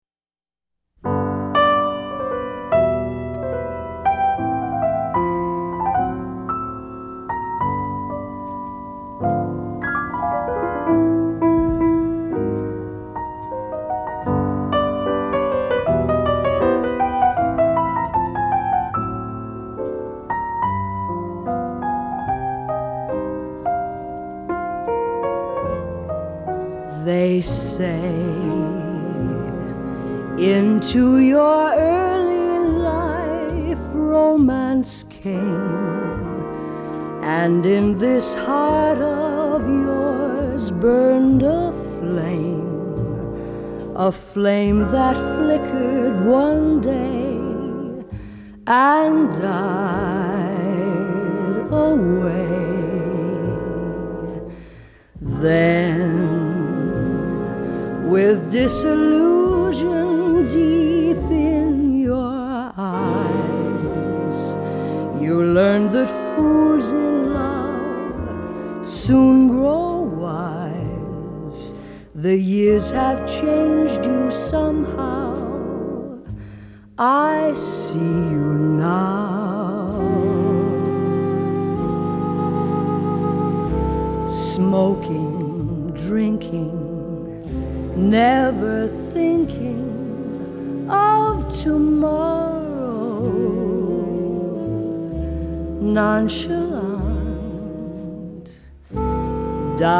頂尖的樂團、一流的編曲，加上動人的演唱